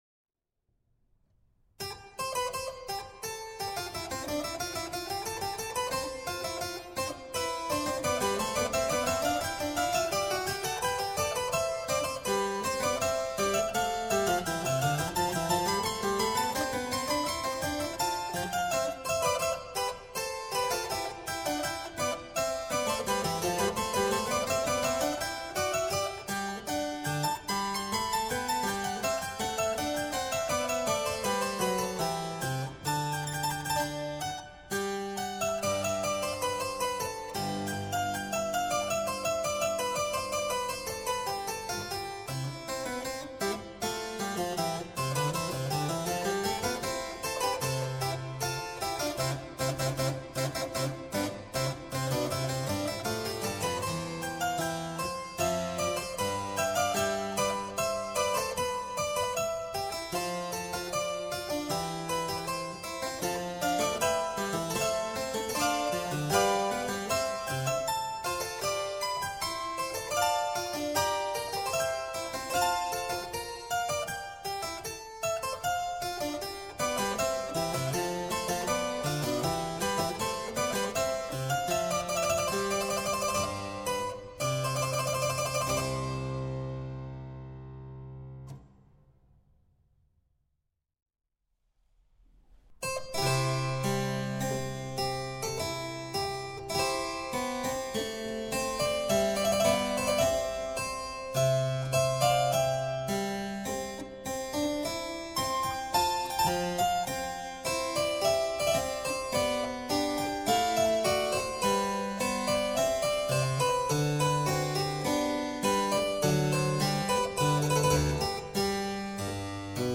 Musikalischer Impuls – 01.8.2020 Silberklang – Musik für Cembalo
Liebe Musikinteressierte, heute hören Sie Musik, die ich auf meinem Cembalo eingespielt habe. Die Aufnahme fand in St. Josef statt.